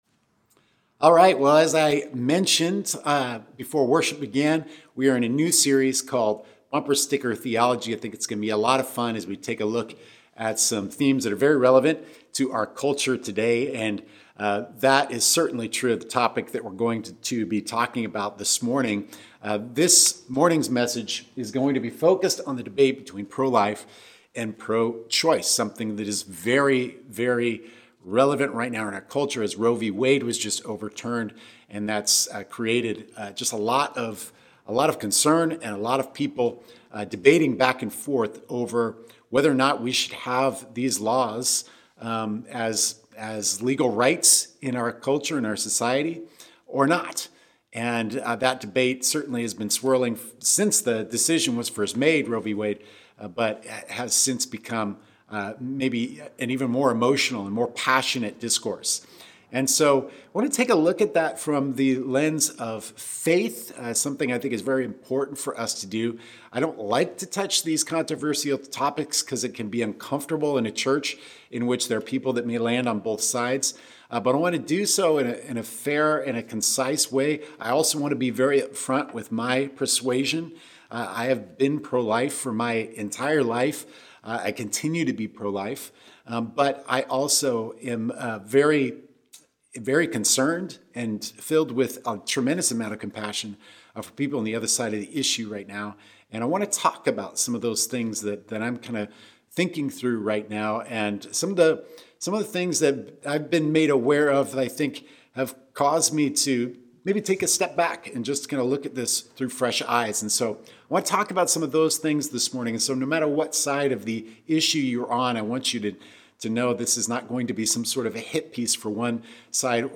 New sermon series starts today!